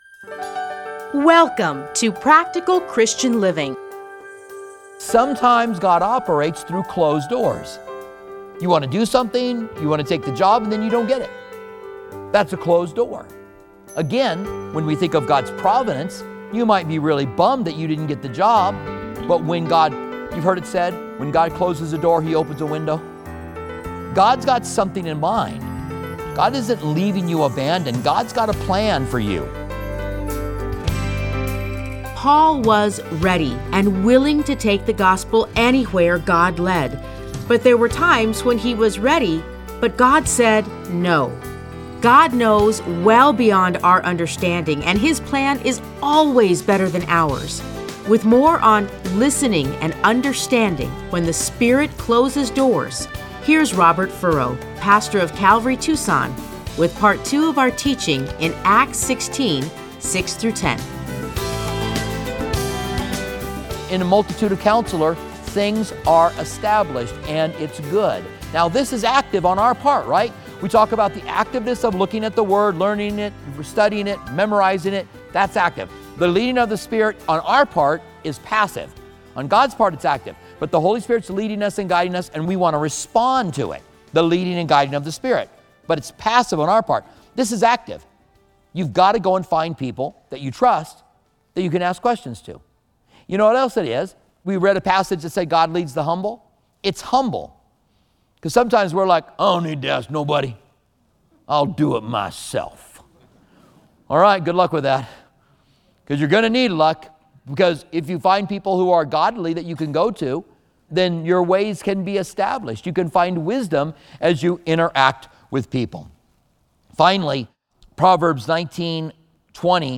Listen to a teaching from Acts 16:6-10.